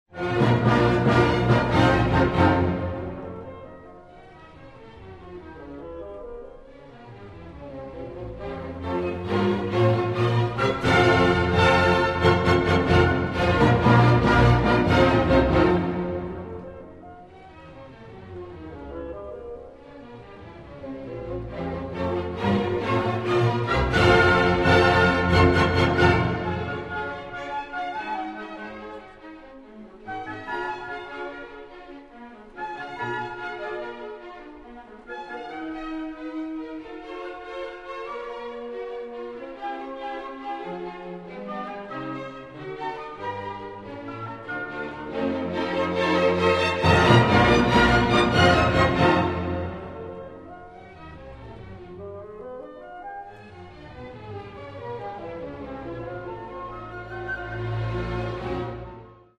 Каталог -> Другое -> Relax-piano, музыкальная терапия
Allegro vivace